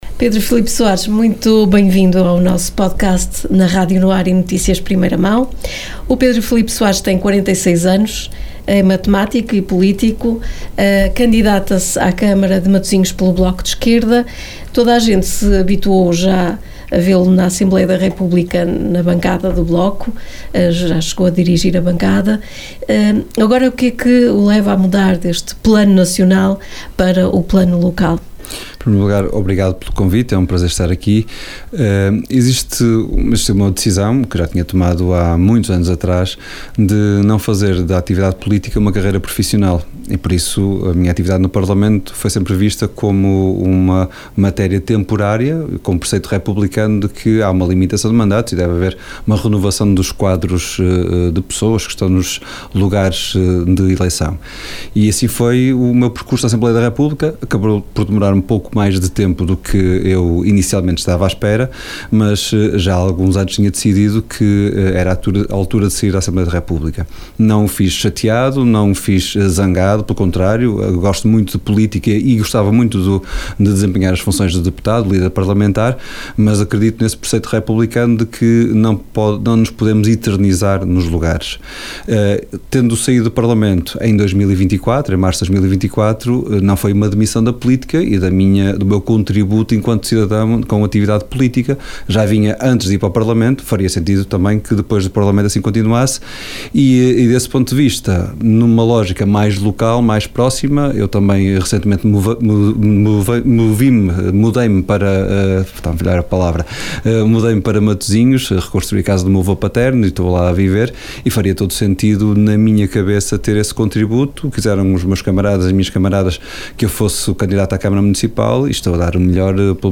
Entrevista-Pedro-F-Soares_SET2025.mp3